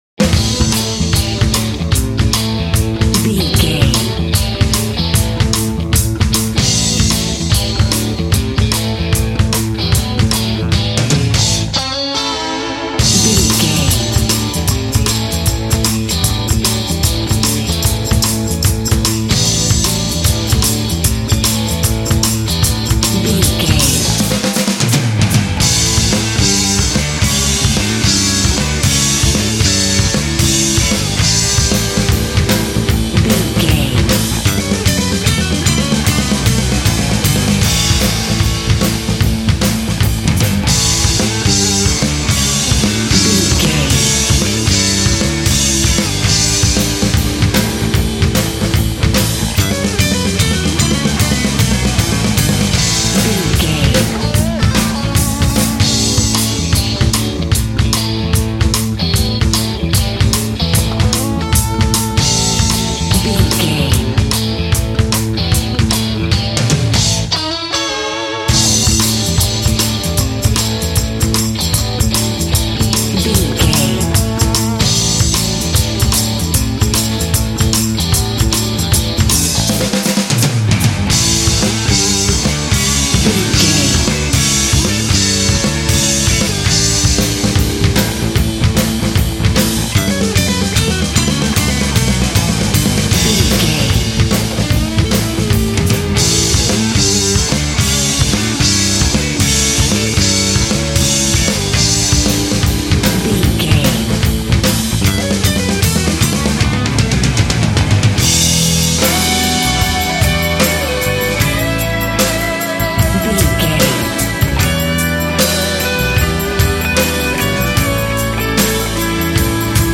Ionian/Major
drums
electric guitar
bass guitar
pop rock
hard rock
lead guitar
aggressive
energetic
intense
powerful
nu metal
alternative metal